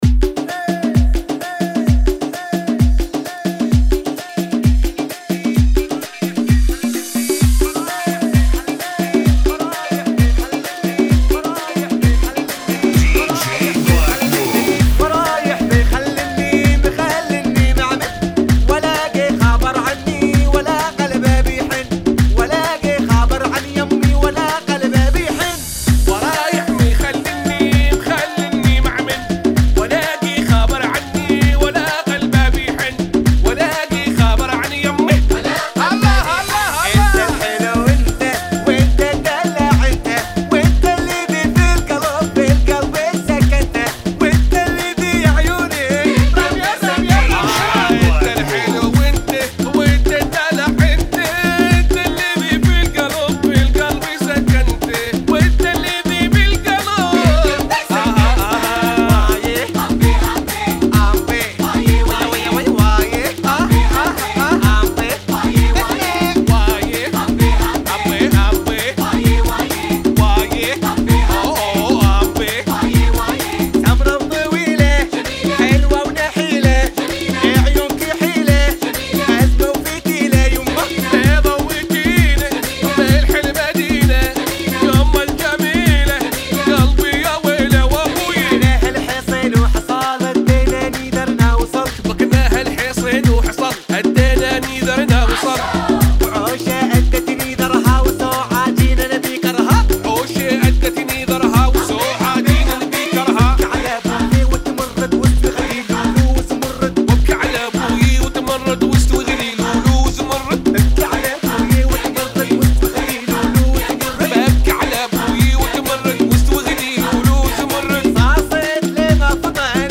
130 Bpm